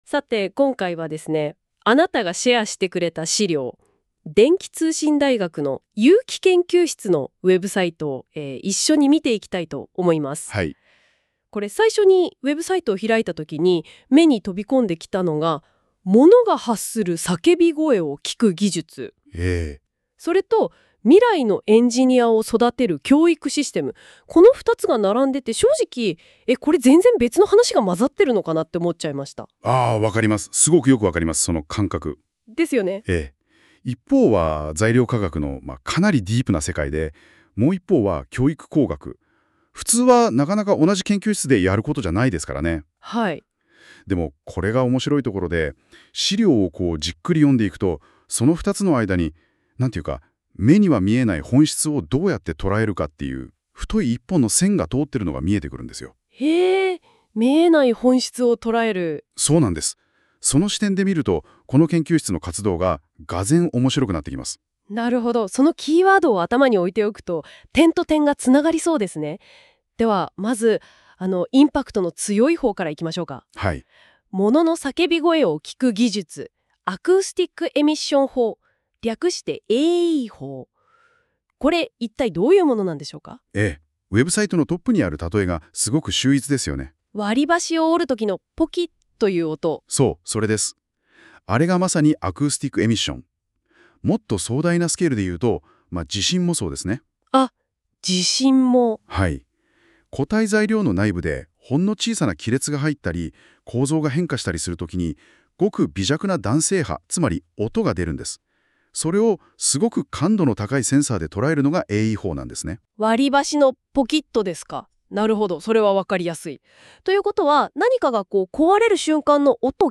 トップページ 研究室概要 沿 革 お問い合わせ English Page 電気通信大学 通機会 音声解説 ・ 動画解説 by NotebookLM 研究室内向け情報 研究内容 アコースティック・エミッションに関する研究 ～“もの”の叫び声を聴く技術～ 割れが生じるなど固体に急激な変化が起こるときに弾性波が発生する現象をアコースティック・エミッション（AE）といいます。
NotebookLM.m4a